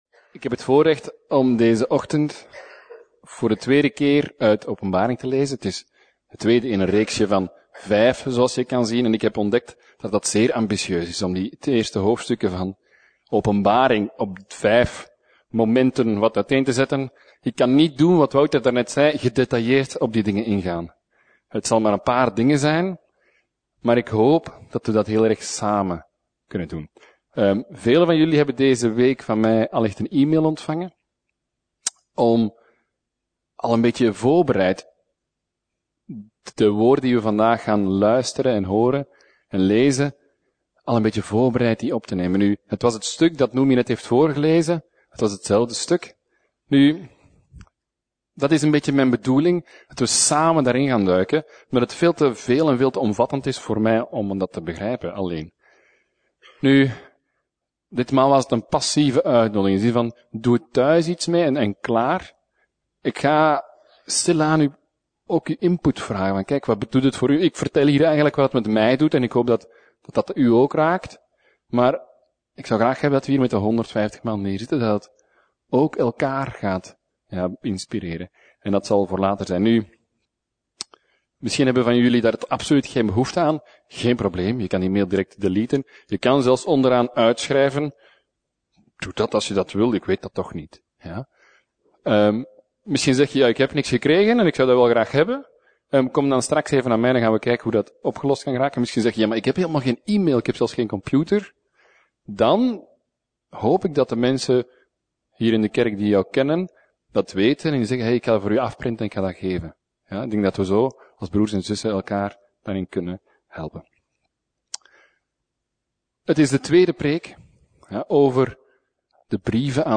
Preek: Brieven van Jezus (2/5) - Levende Hoop